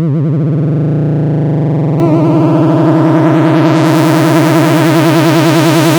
A software synth programmed in Haskell.